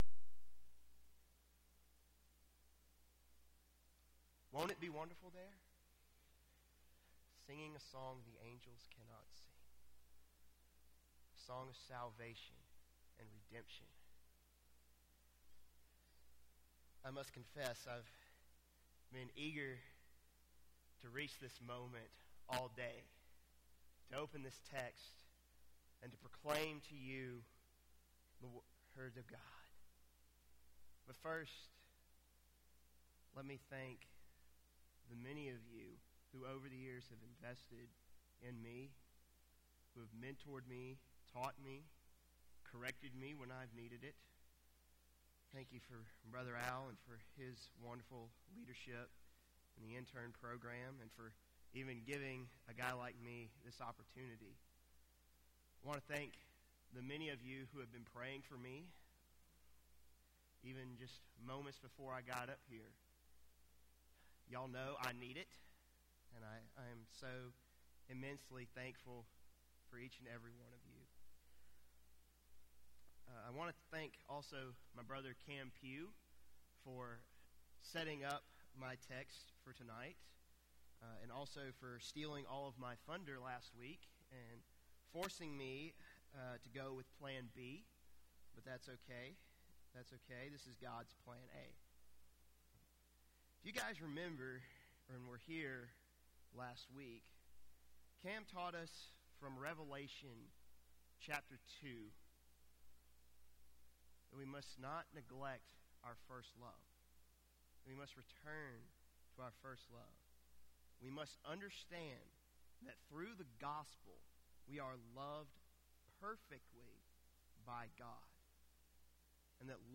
Lakeview Baptist Church - Auburn, Alabama
Seminary Intern Sermon Date